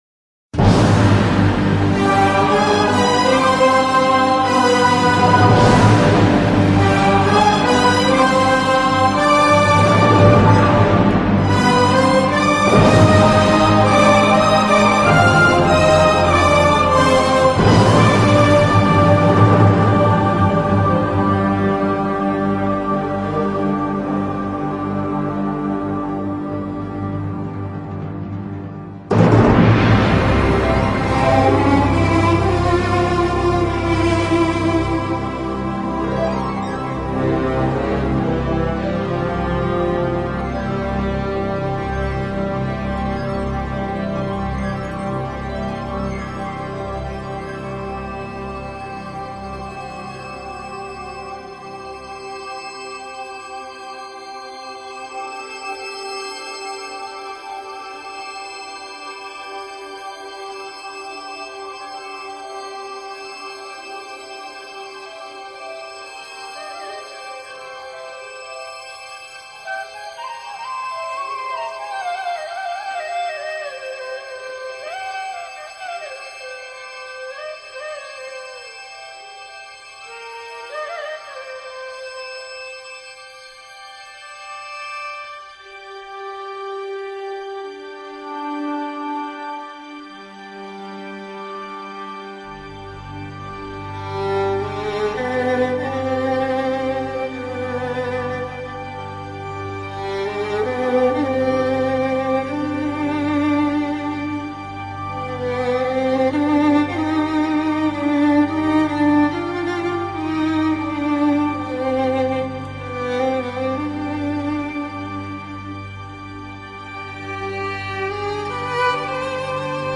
Música-egipcia.mp3